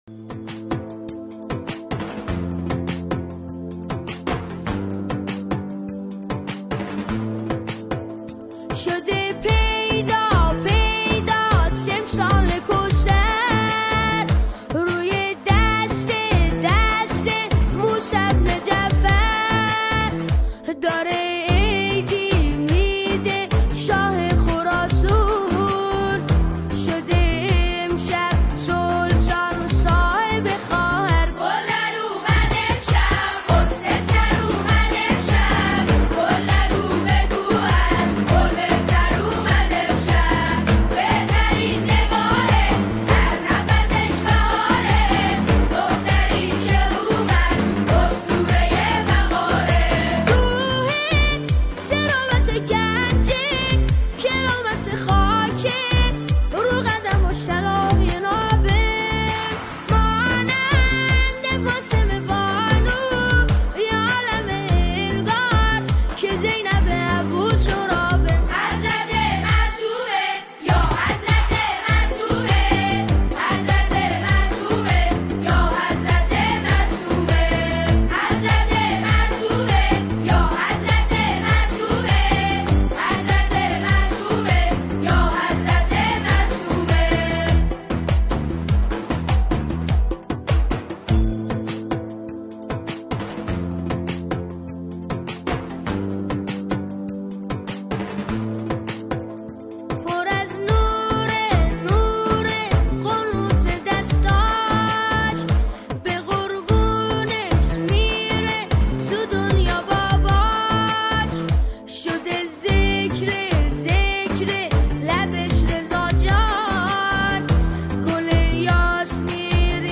با صدای دلنشین